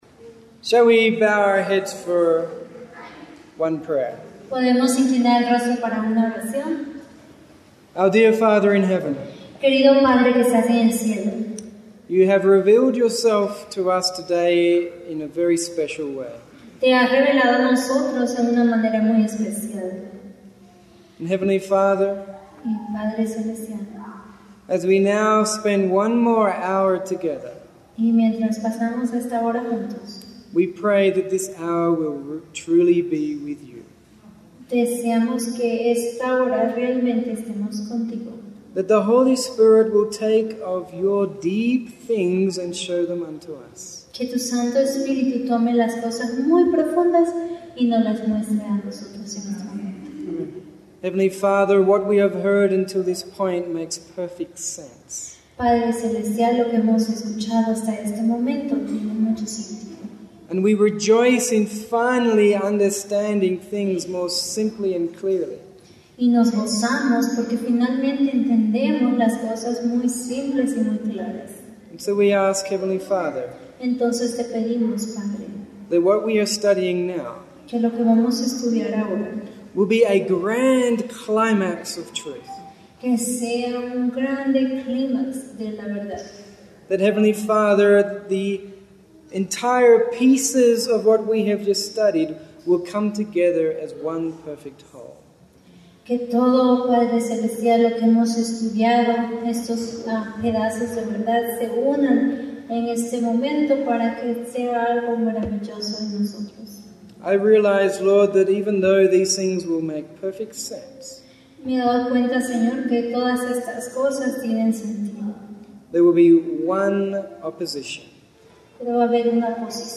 We recently gave a series of lectures on Righteousness by Faith with a large group in Mexico City and it is now our pleasure to provide you with a recording that includes Spanish translation.